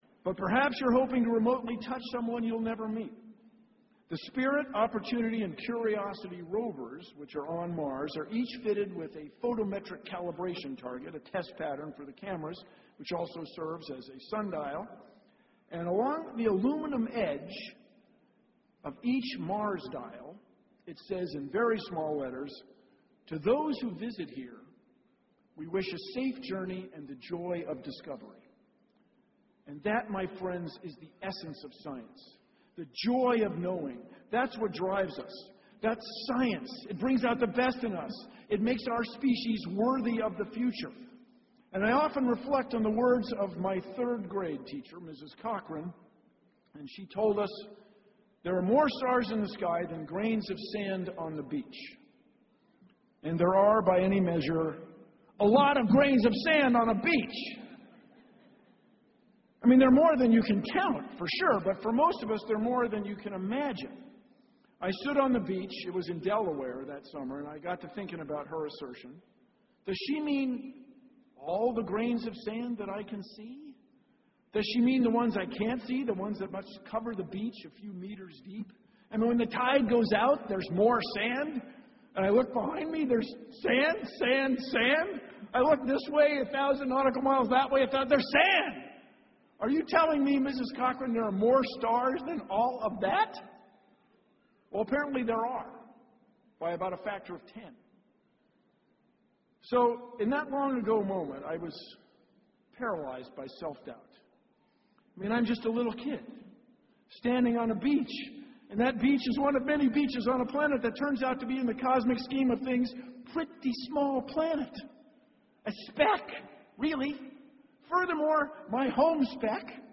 公众人物毕业演讲 第171期:比尔·奈马萨诸塞大学2014(18) 听力文件下载—在线英语听力室